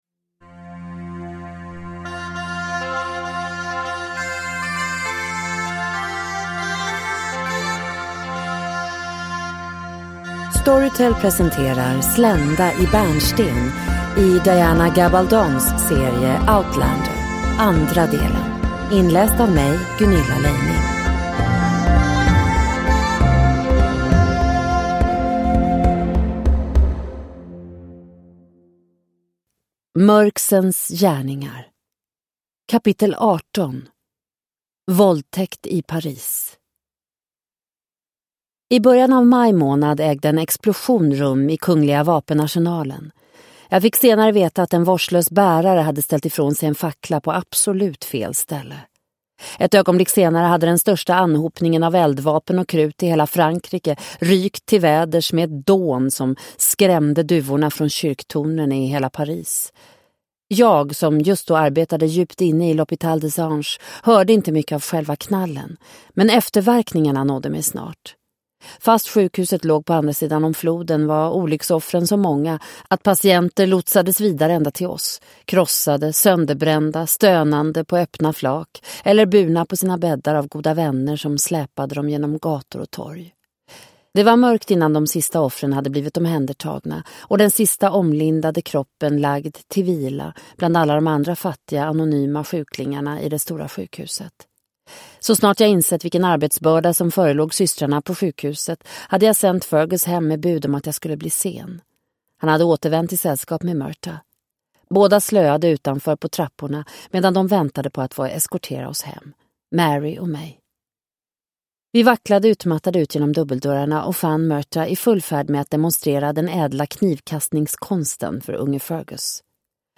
Slända i bärnsten - del 2 – Ljudbok – Laddas ner